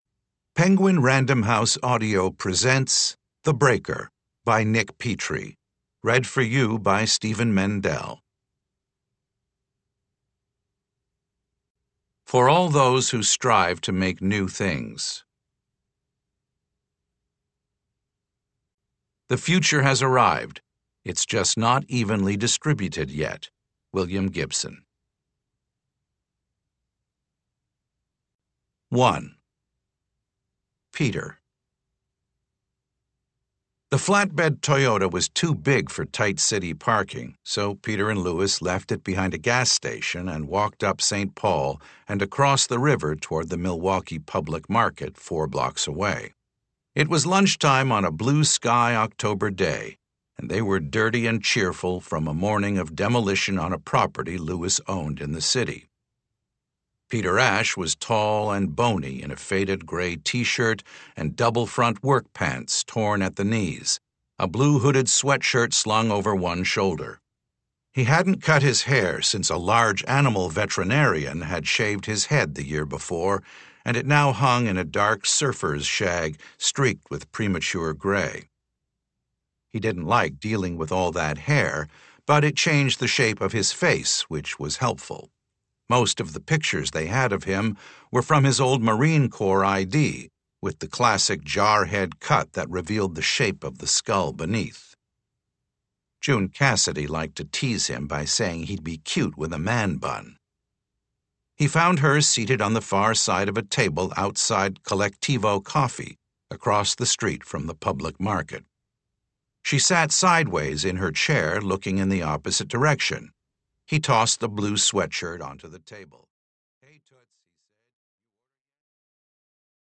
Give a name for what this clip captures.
Edition: Unabridged